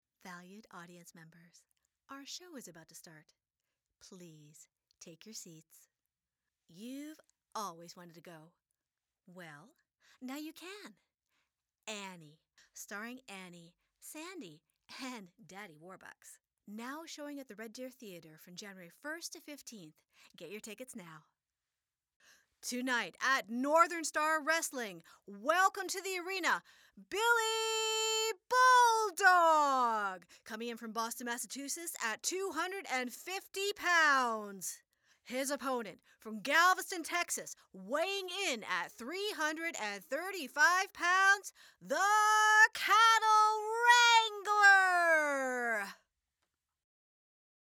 Female
Live Announcer
Theatre And Wrestling Vo